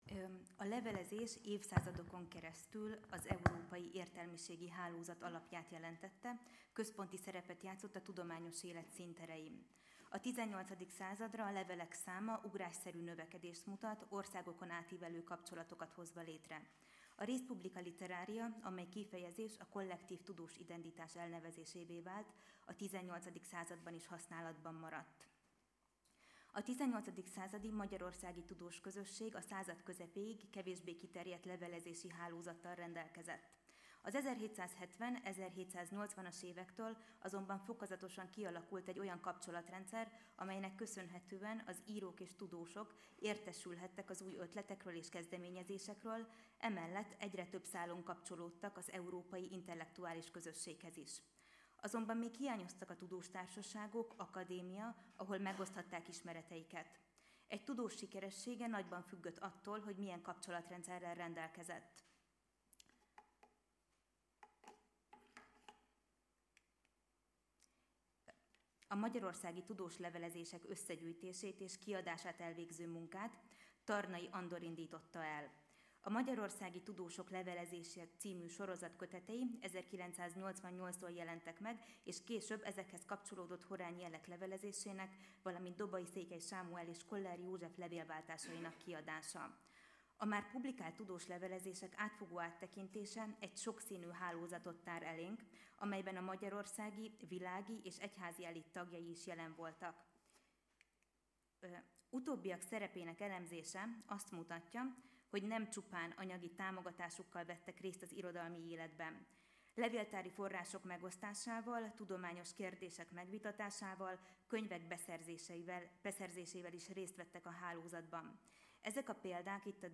Tizenegyedik ülés